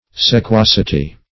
Search Result for " sequacity" : The Collaborative International Dictionary of English v.0.48: Sequacity \Se*quac"i*ty\, n. [L. sequacitas.] Quality or state of being sequacious; sequaciousness.
sequacity.mp3